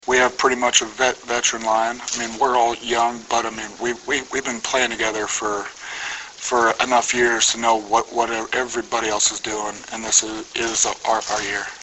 Preseason Press Conference